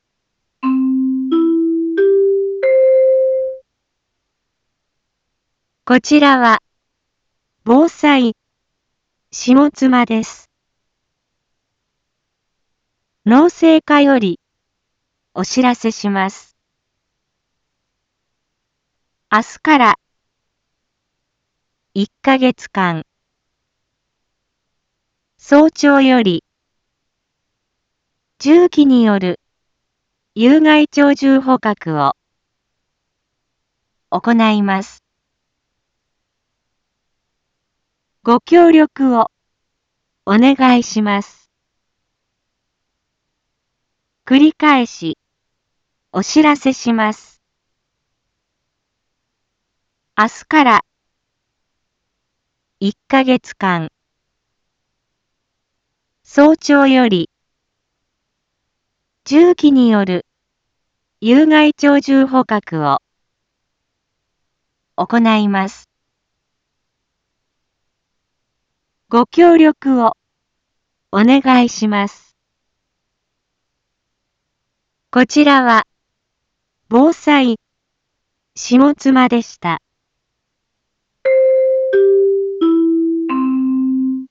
Back Home 一般放送情報 音声放送 再生 一般放送情報 登録日時：2021-05-28 07:11:28 タイトル：有害鳥獣捕獲の実施について（千代川） インフォメーション：こちらは、防災下妻です。